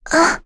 Jane-Vox_Damage_01.wav